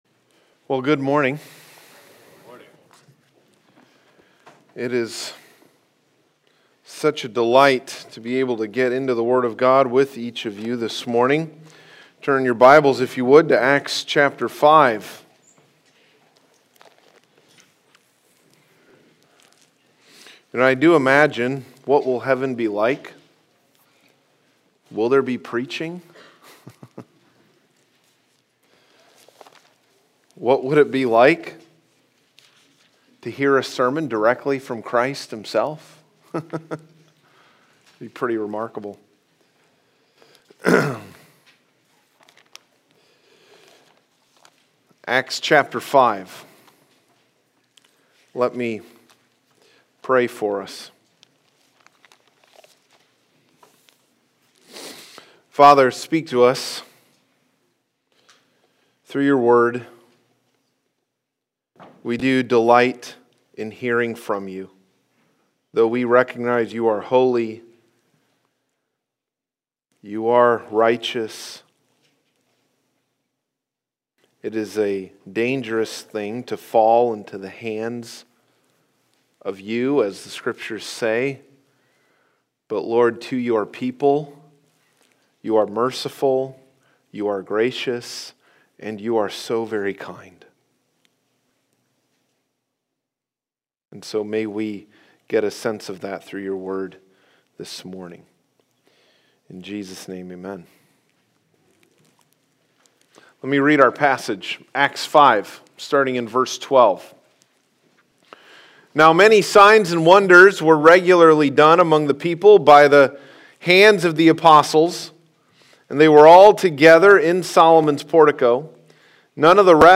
Passage: Acts 5:12-16 Service Type: Sunday Morning « Sin